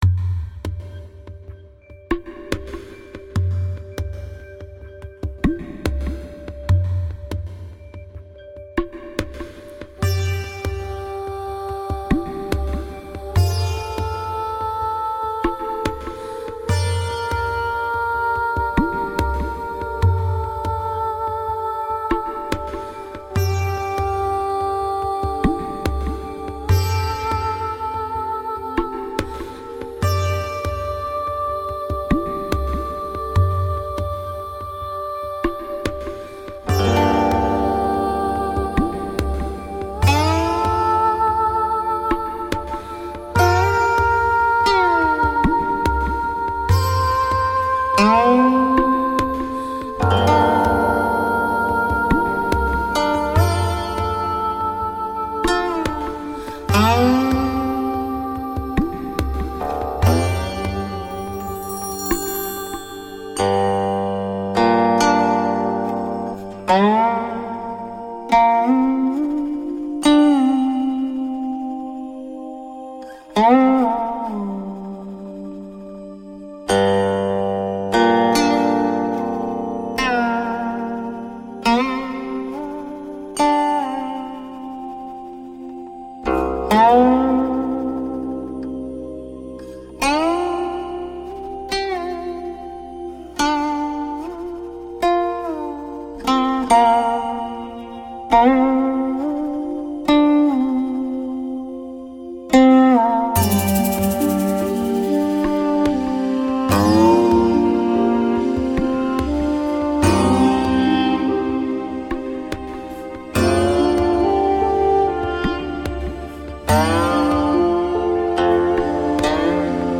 东方禅意音乐
古琴